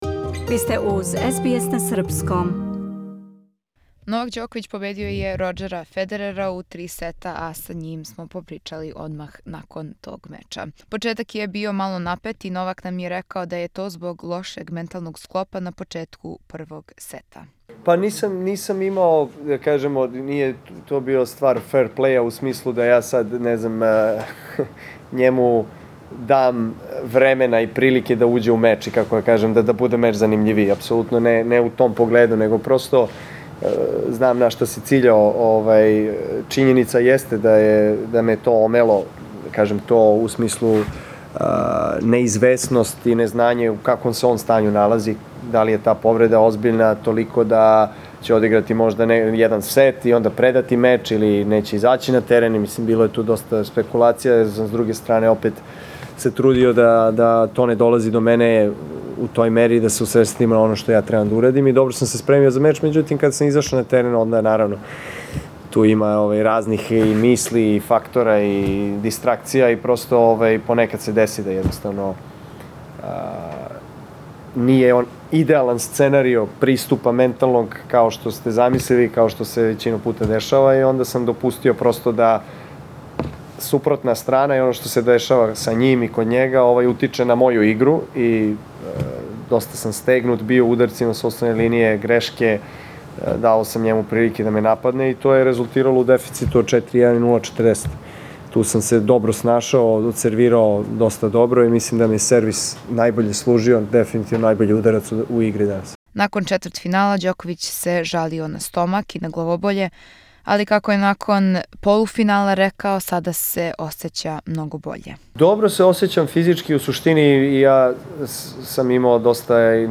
Српски тенисер Новак Ђоковић вечерас игра у финалу АО против Аустријанца Доменика Тима. Послушајте интервју са Новаком пред одлучујући меч.